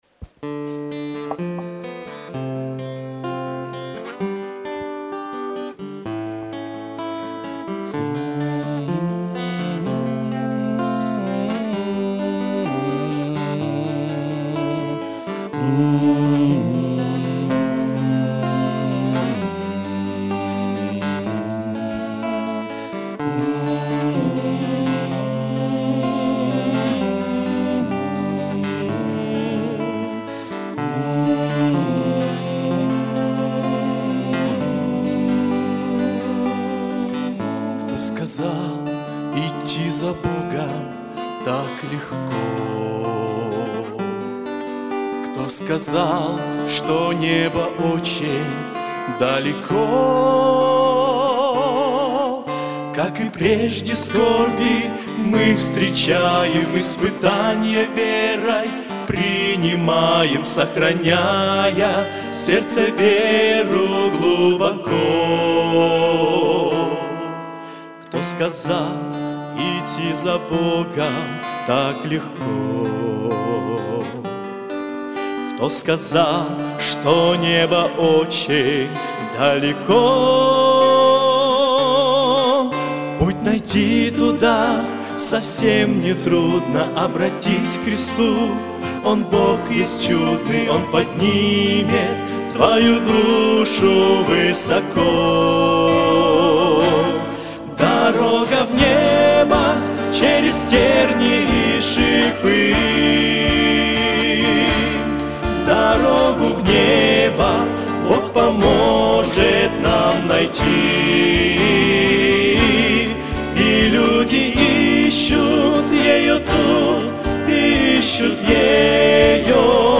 Богослужение 06.10.2024
Дорога в небо - Братья (Пение)[